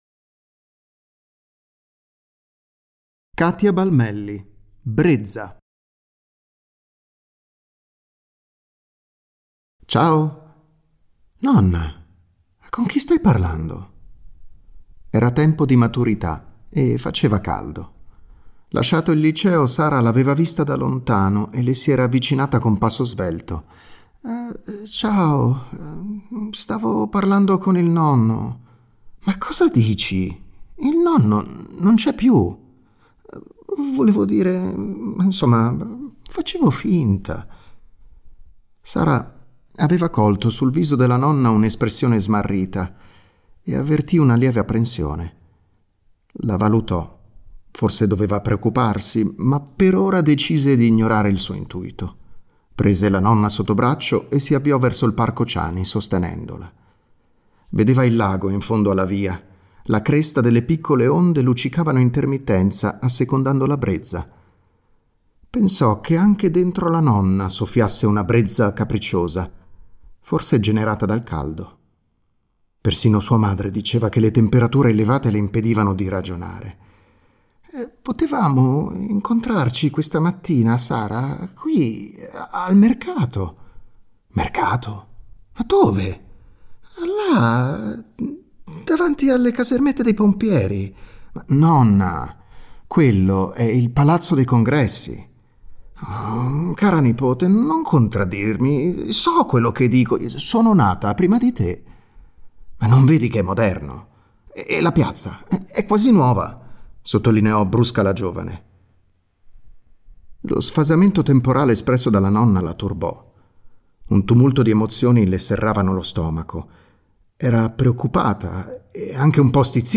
24 scrittrici e scrittori per 24 racconti. 6 attrici e attori a interpretare le 24 storie. 1 videoartista. 24 schermi distribuiti nel centro storico.